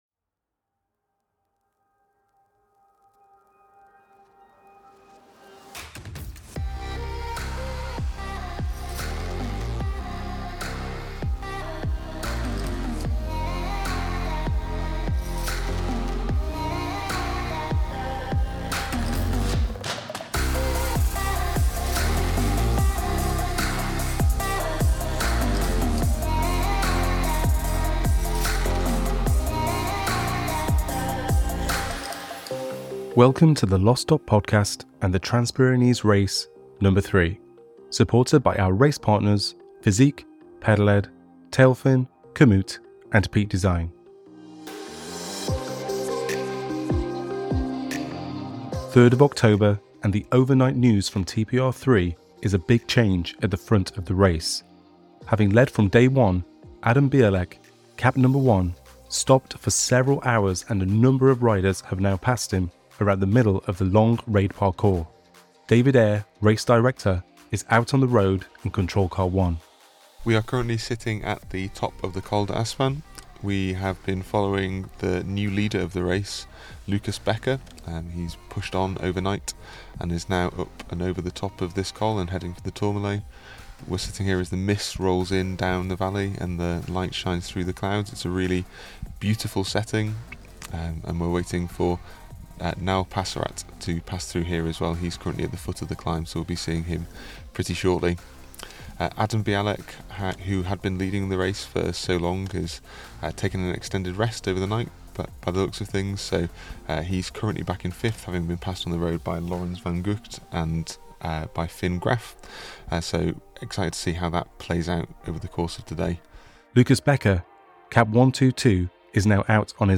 Get your earfuls of daily rider accounts, and hear the very sounds and voices of the Race itself, via our Race Reporters and rider recordings on day 05, covering everything from special moments at the lighthouse at the end of Parcours 4 to roadside power naps all the way to SJdL.